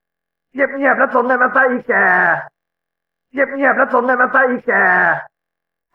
เสียงเรียกเข้าเดินติดแล้ว ริงโทนมันมีไอ้จนมาโวยวาย
หมวดหมู่: เสียงเรียกเข้า